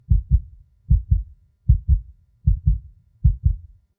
Heartbeat
A steady, rhythmic human heartbeat with clear lub-dub pattern and chest resonance
heartbeat.mp3